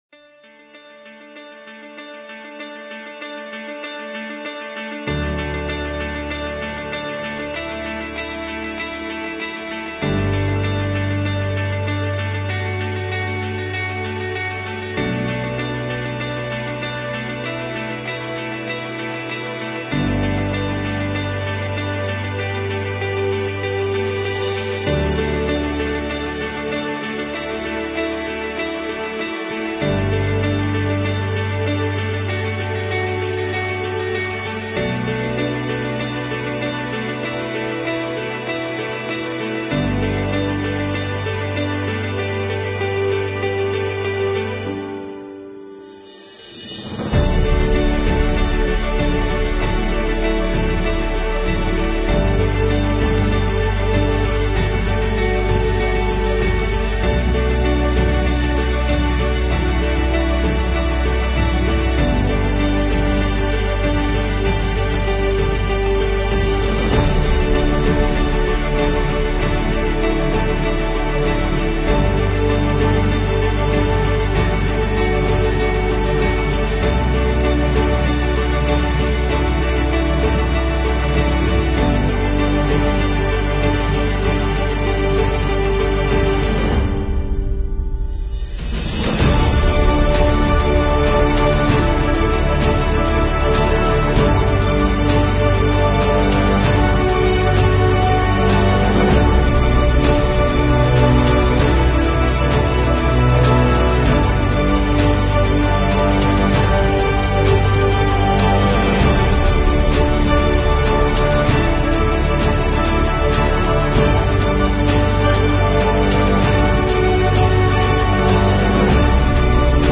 16位立体声